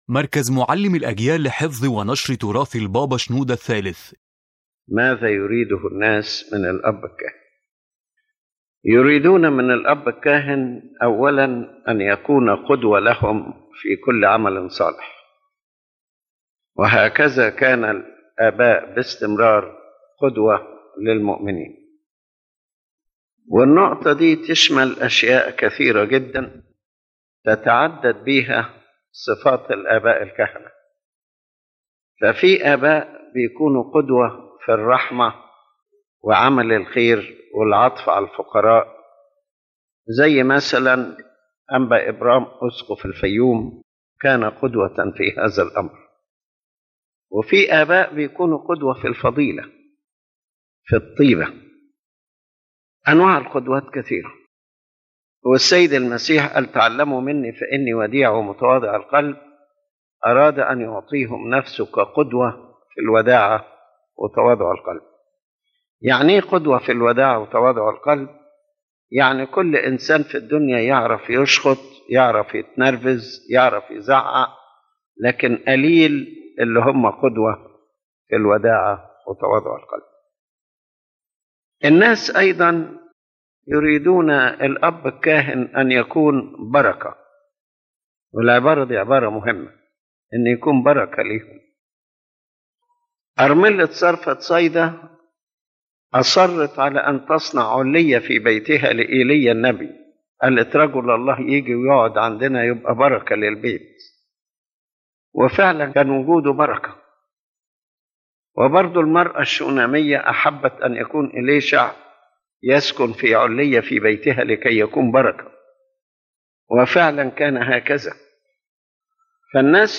The General Message of the Lecture